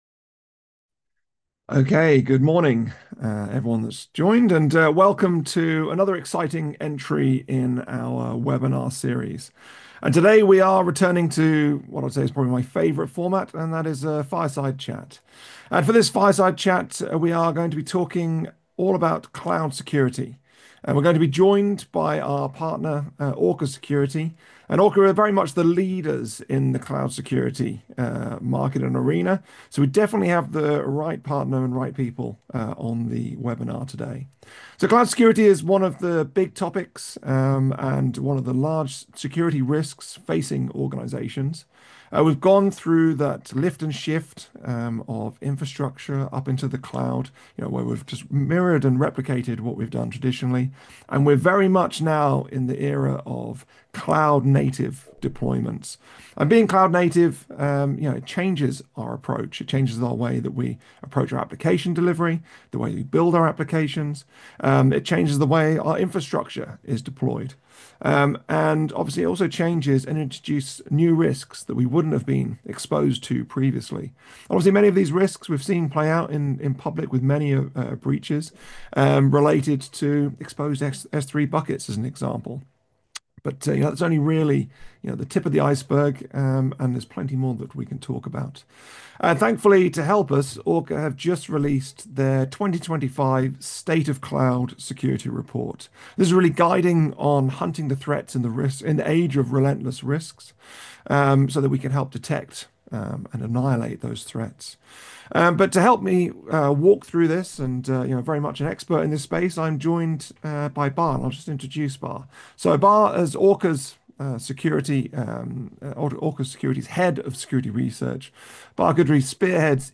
Audio-only-webinar-Orca-cloud.m4a